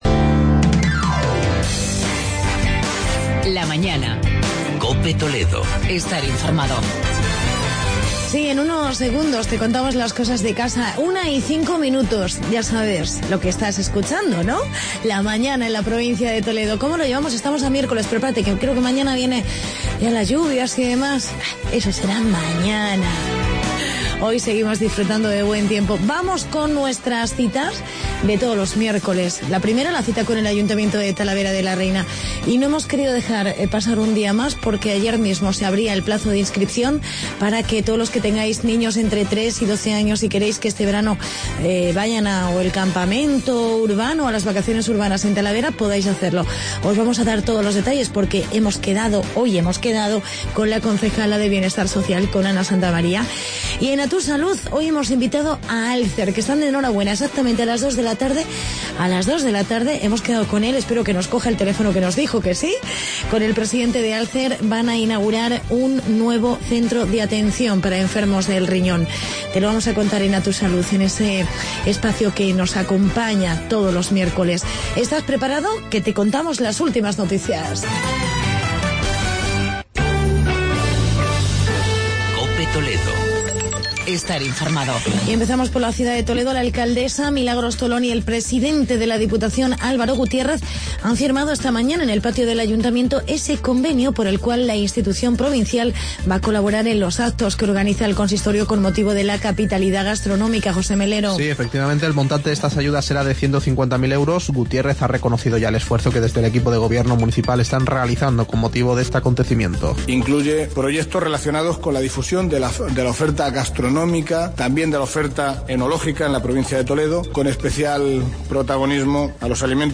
Entrevista con la concejal Ana SantaMaría sobre campamentos urbanos